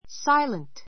silent sáilənt